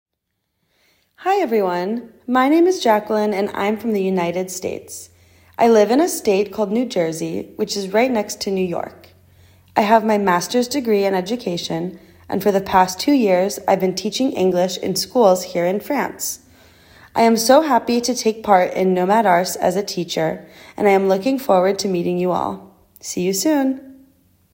The english teachers are talking to you!